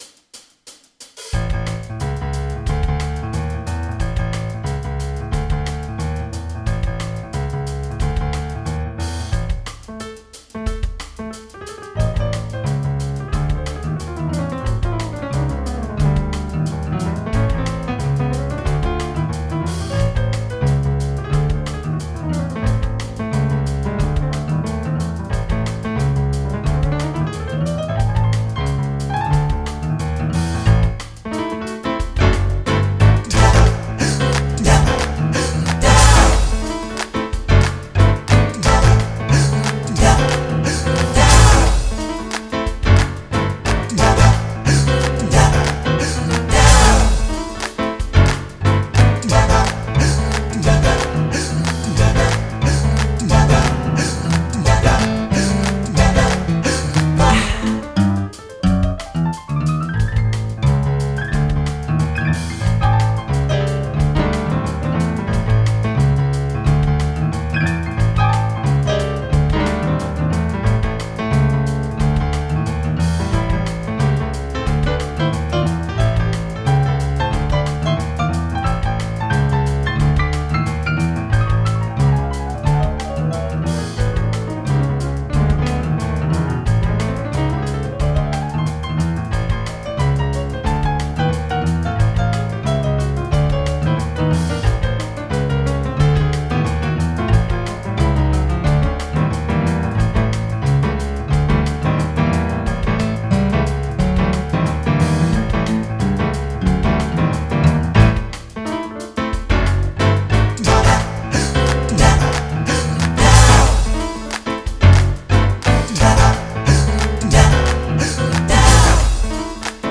Just meant to be upbeat.